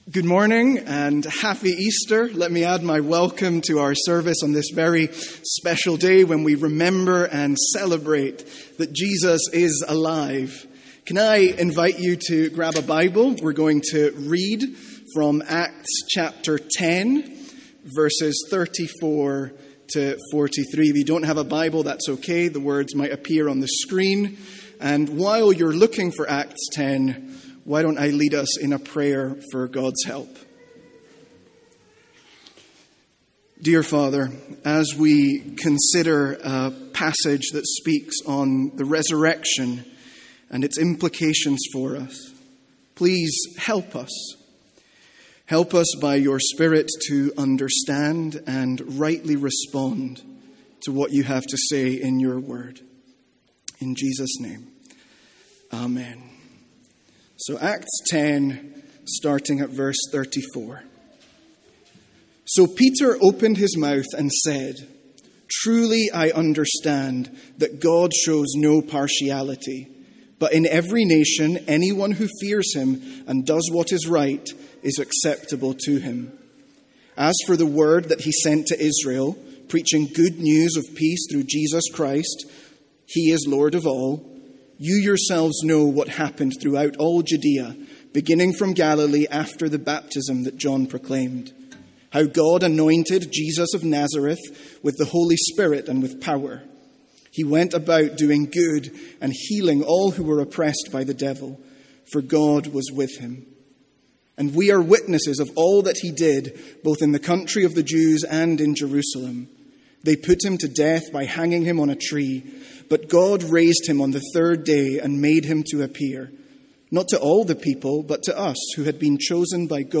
From our easter morning service.